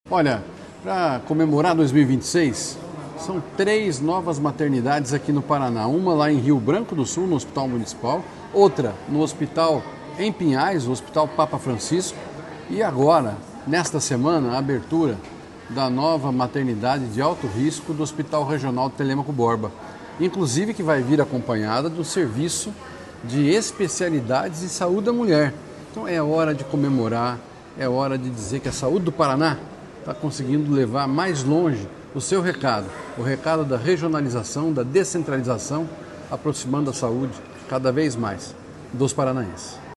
Sonora do secretário da Saúde, Beto Preto, sobre as novas maternidades do Paraná